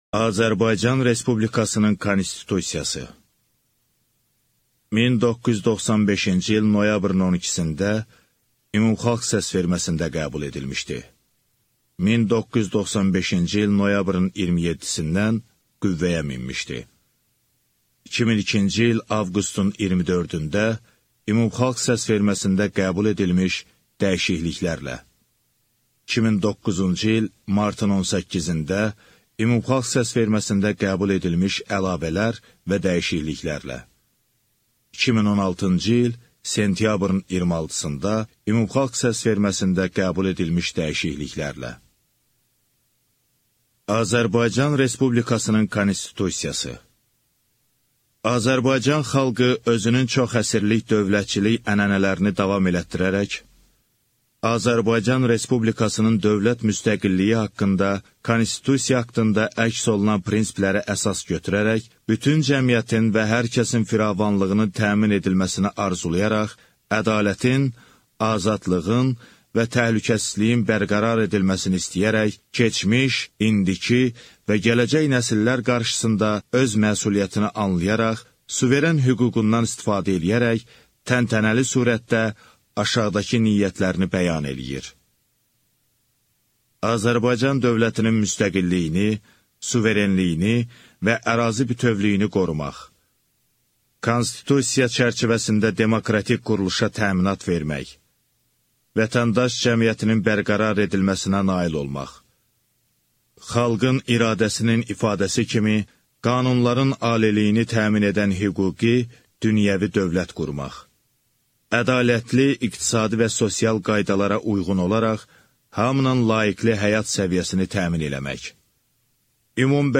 Аудиокнига Azərbaycan Respublikasının Konstitusiyası | Библиотека аудиокниг